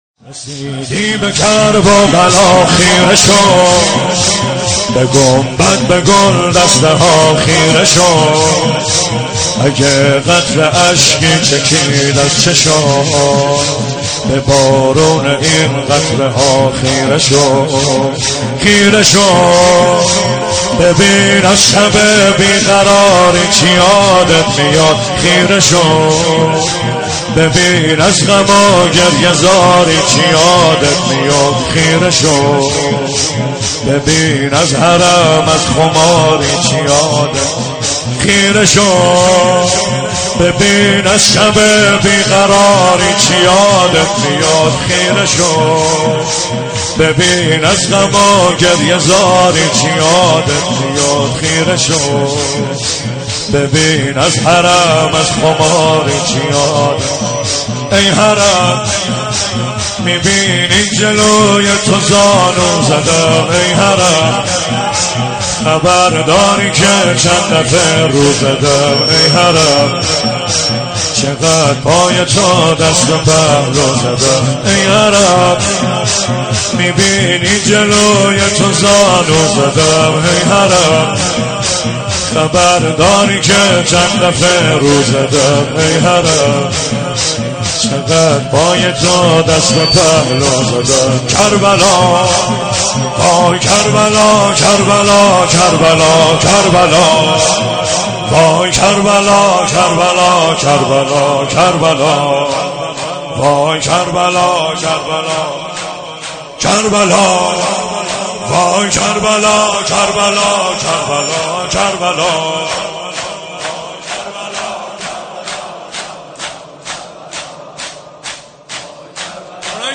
شور اربعین